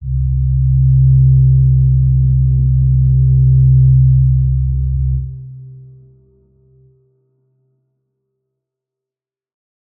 G_Crystal-C3-f.wav